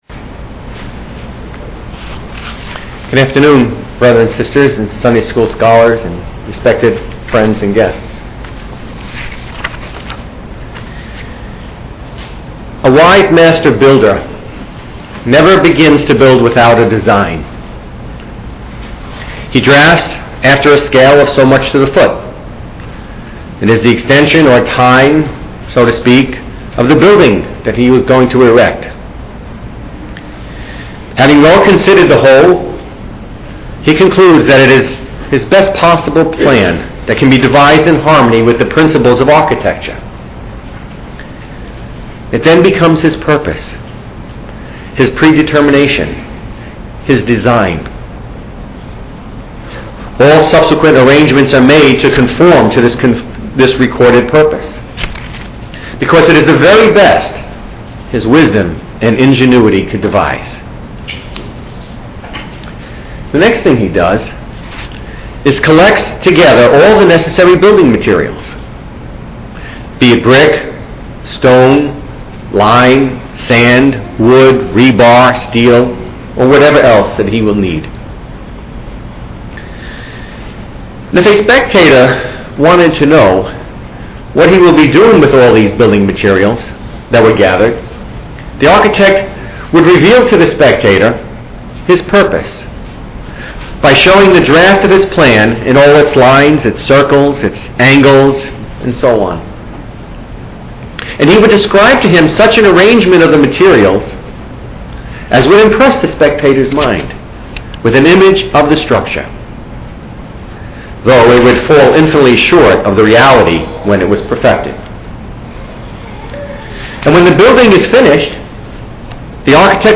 The recordings of the talks from the 2014 Tennessee Gathering are now available for downloading.